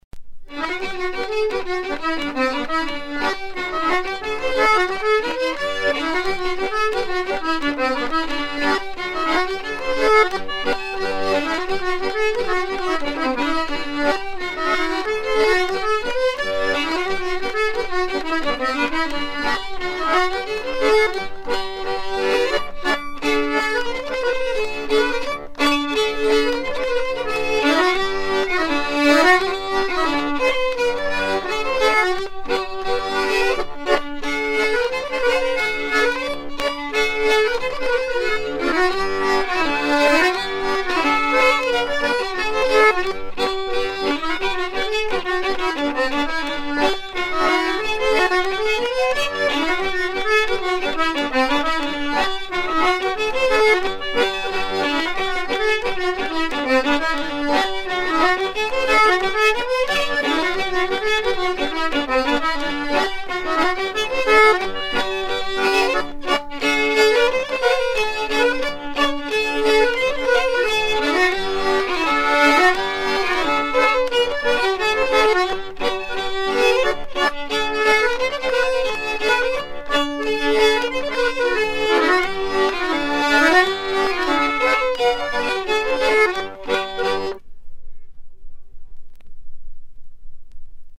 Air à danser de Flandres
danse : hornpipe